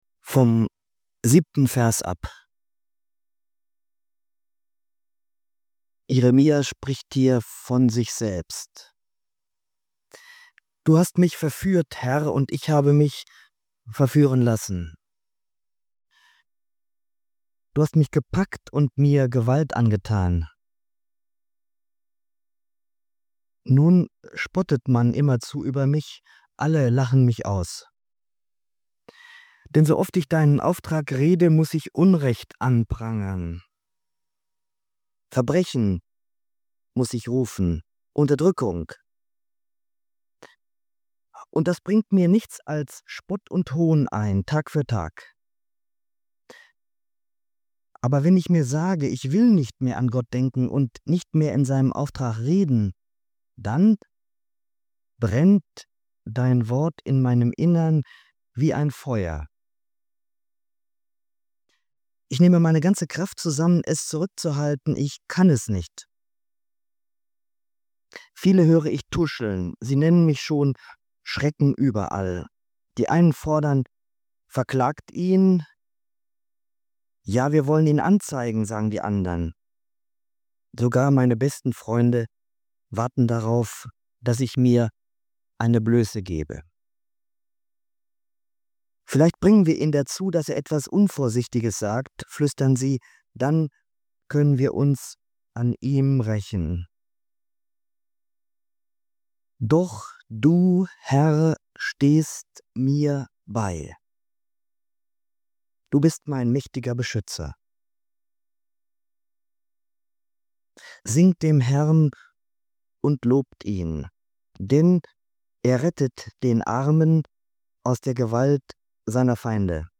Eine Predigt über Nachfolge, Standhaftigkeit und die Hoffnung, dass Gottes Nähe gerade in schweren Zeiten am stärksten erfahrbar wird.